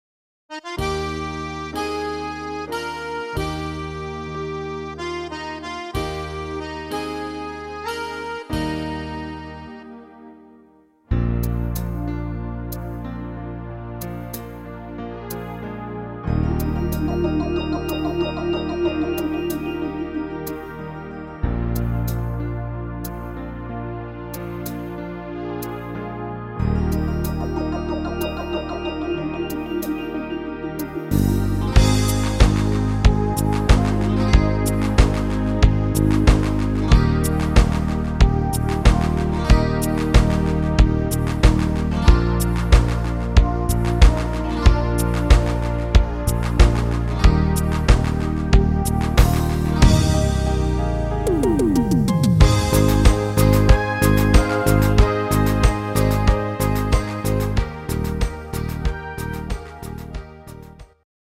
neue fetzige Version